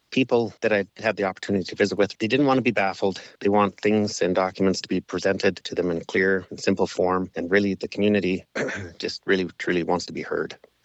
Clearwater County Reeve Jordon Northcott speaking to what he heard from voters this fall